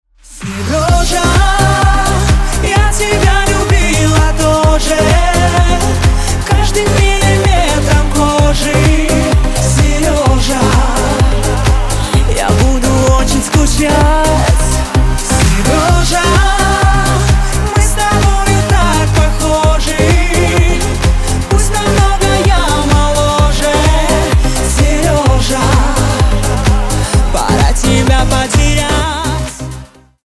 • Качество: 320, Stereo
поп
попса